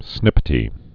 (snĭpĭ-tē)